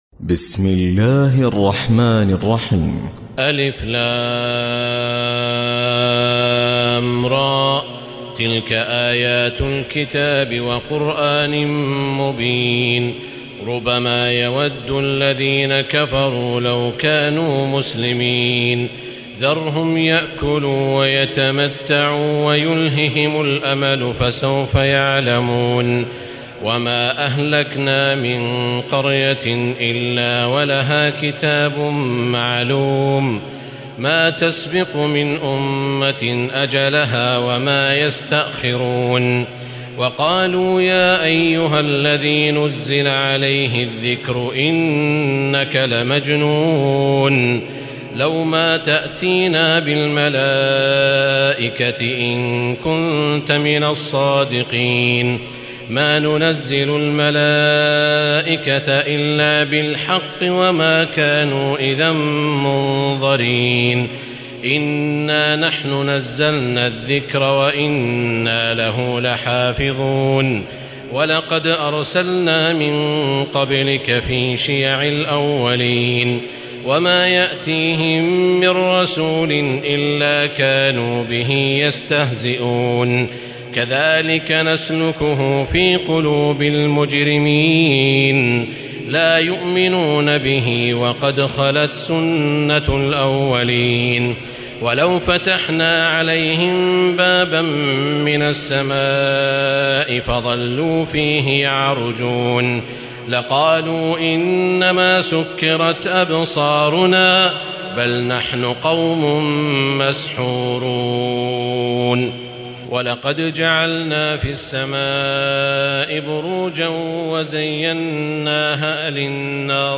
تراويح الليلة الثالثة عشر رمضان 1437هـ من سورتي الحجر كاملة و النحل (1-44) Taraweeh 13 st night Ramadan 1437H from Surah Al-Hijr and An-Nahl > تراويح الحرم المكي عام 1437 🕋 > التراويح - تلاوات الحرمين